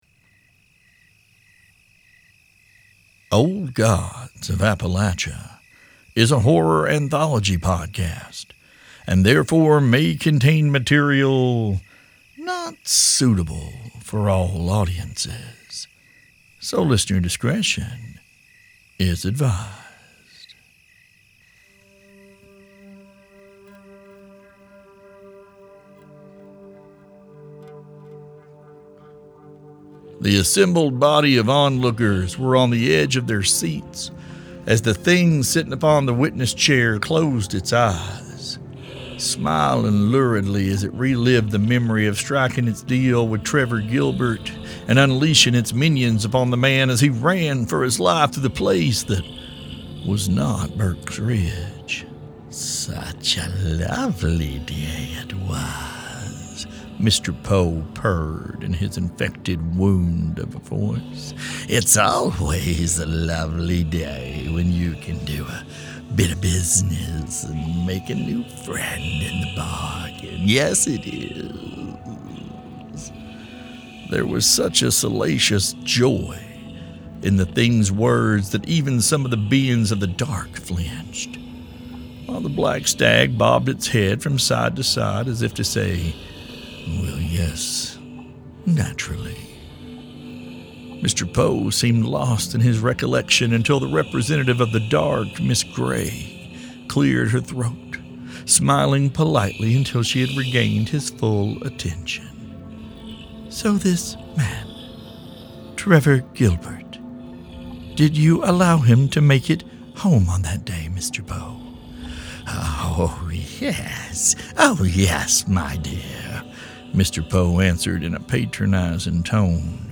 CW: Woodland ambiance, monster noises, gunshot (at low volume), discussion of hunting animals for food, pregnancy and possible complications, light mutilation.